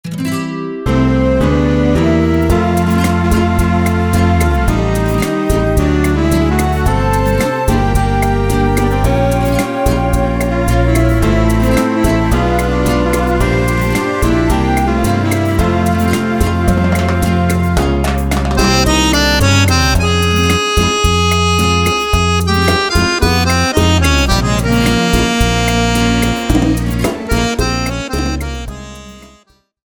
Playback - audio karaoke für Akkordeon